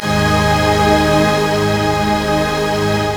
PADY CHORD04.WAV